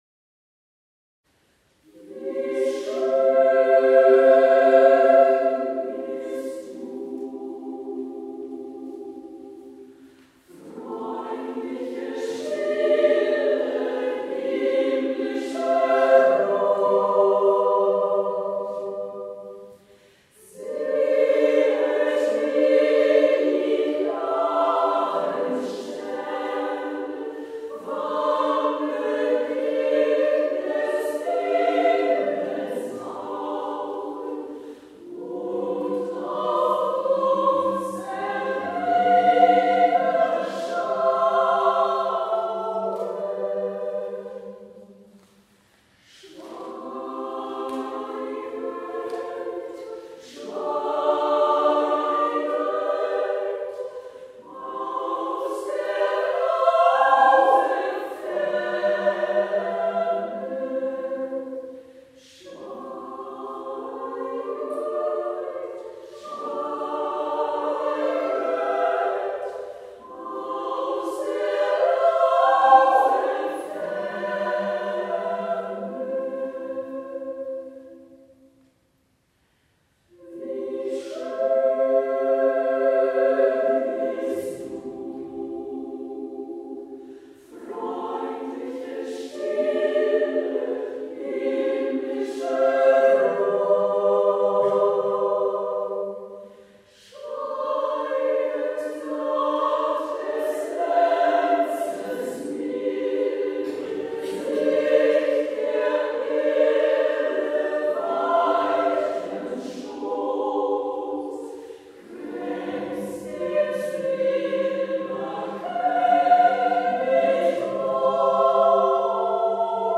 Ein lang gehegter Wunsch: Giuseppe Verdis „Laudi alla Vergine Maria“ für 4-stimmigen Frauenchor mit meinem Schülerkreis realisieren zu können.